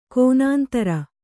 ♪ kōnāntara